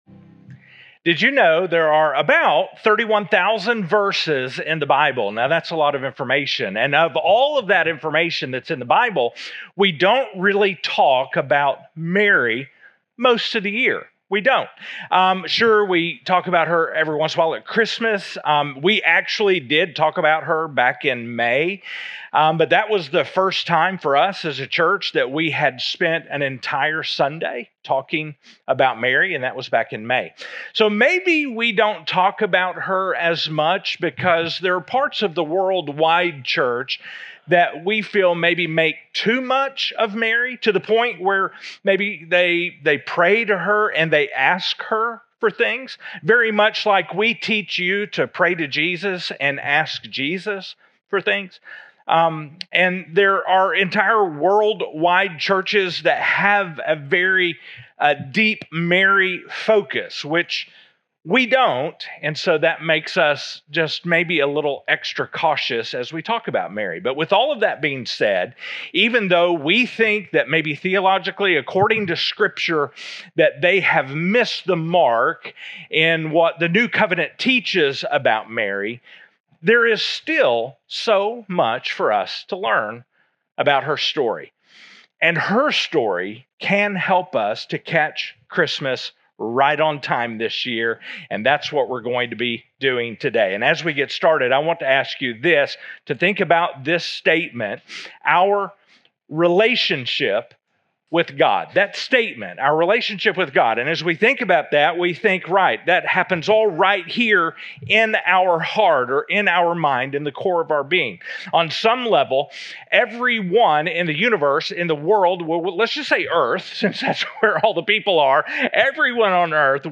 2024 Current Sermon 6 of 9|CATCHING CHRISTMAS CATCHING CHRISTMAS RIGHT ON TIME...